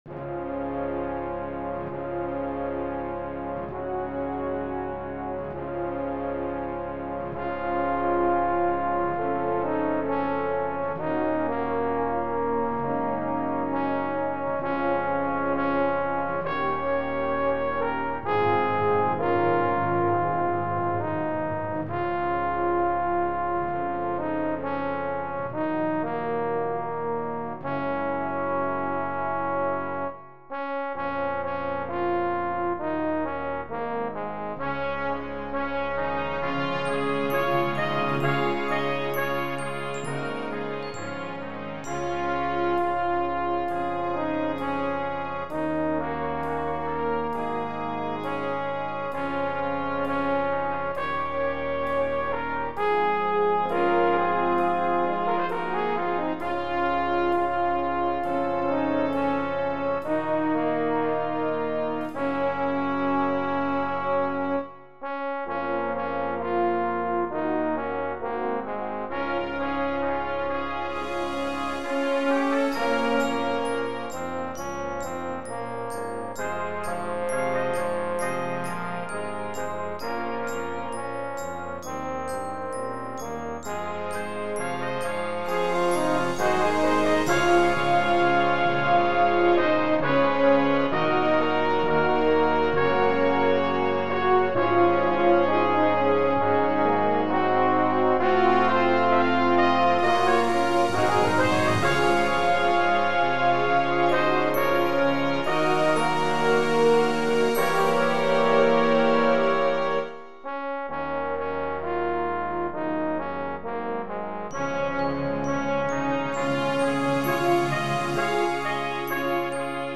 Voicing: 11 Brass and Percussion